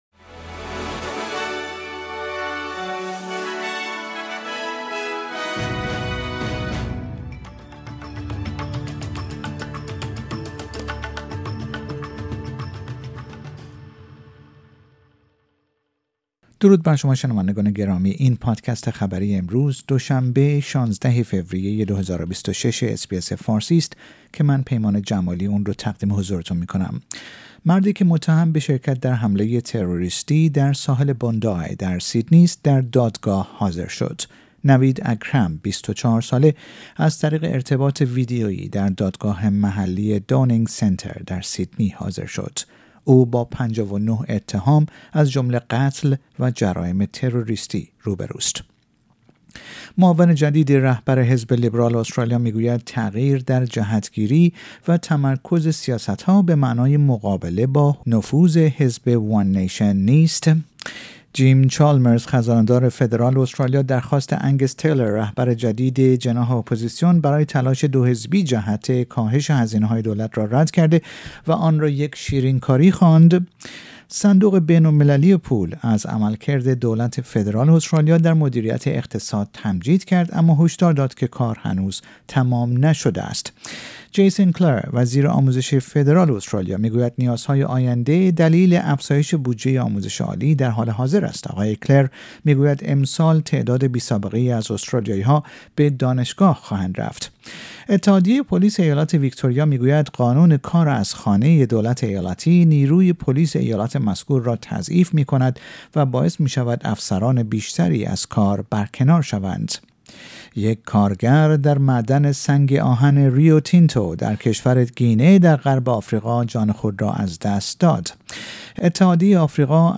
در این پادکست خبری مهمترین اخبار روز دوشنبه ۱۶ فوریه ۲۰۲۶ ارائه شده است.